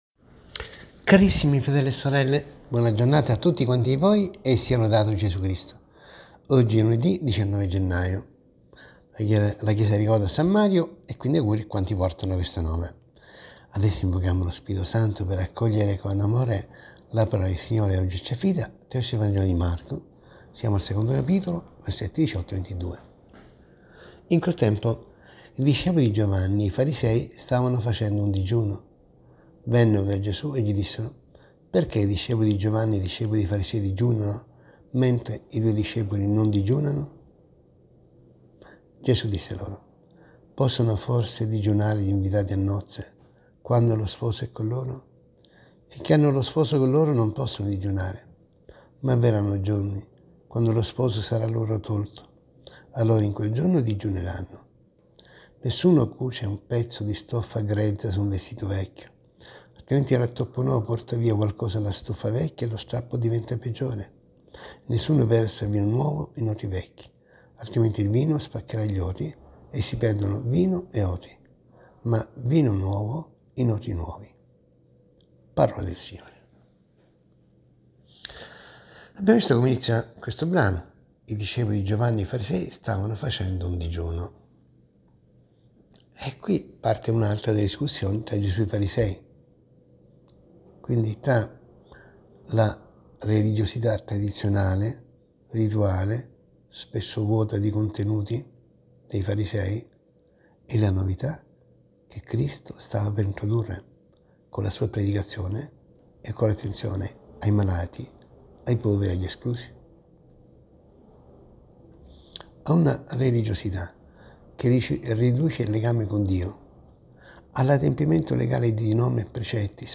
ASCOLTA  RIFLESSIONE SULLA PAROLA DI DIO, - SE L'AUDIO NON PARTE CLICCA QUI